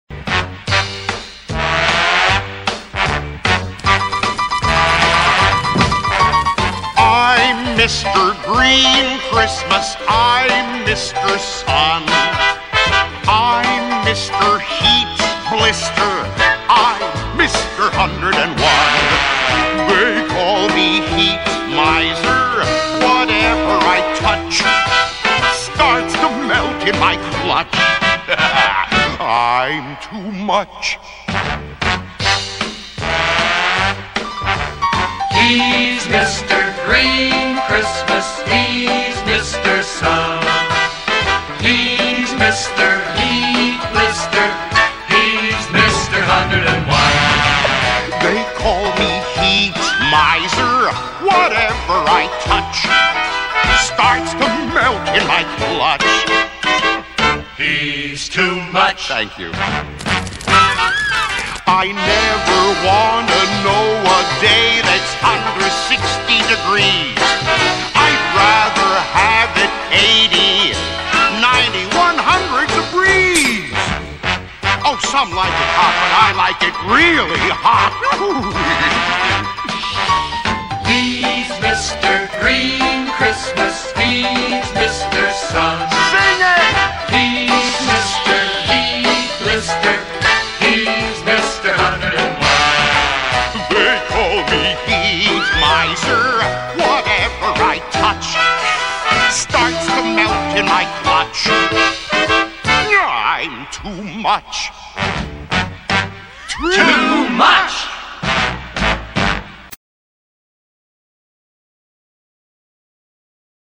Audio - Song:
(better quality than above)